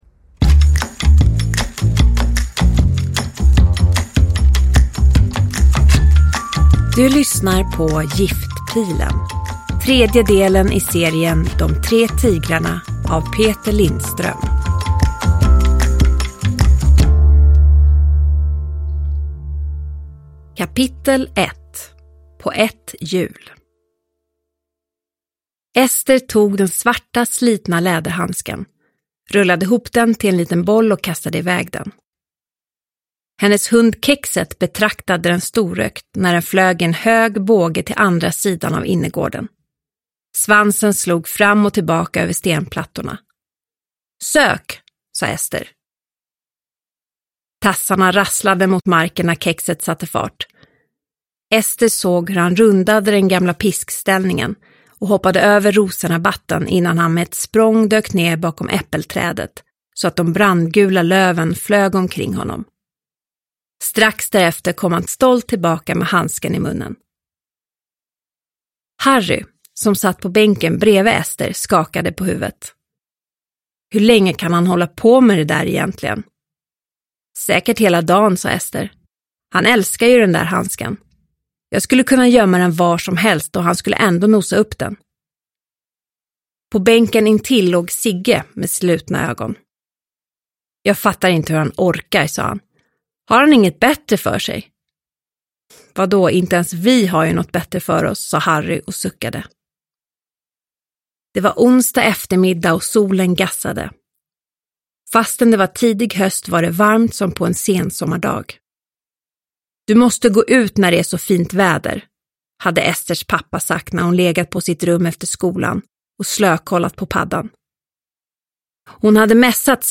Giftpilen – Ljudbok – Laddas ner
Uppläsare: Hanna Alström